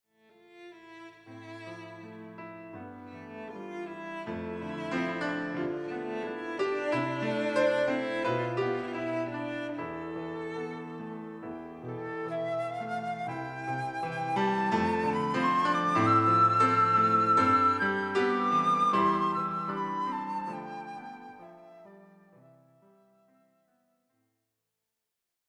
- Recorded and mixed at AVAF Studios, Zurich, Switzerland